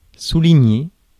Ääntäminen
IPA: /su.li.ɲe/